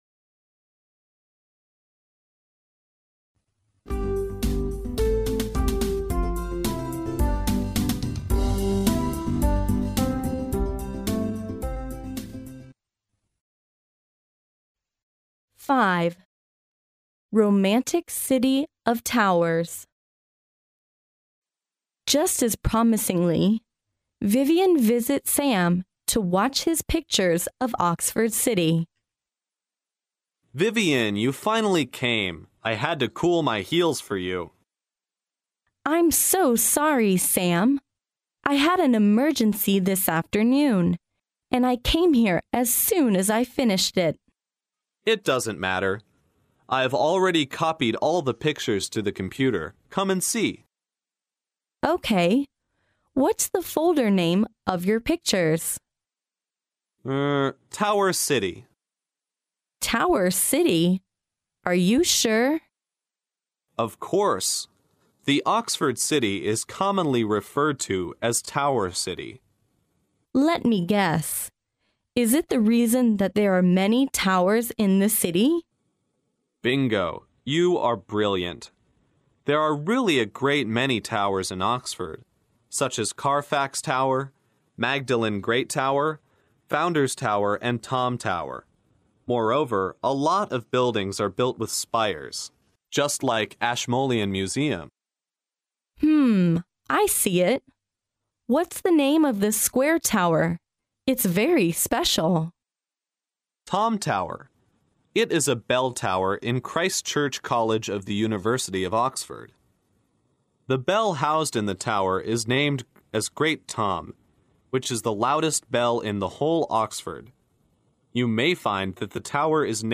牛津大学校园英语情景对话05：浪漫塔城（mp3+中英）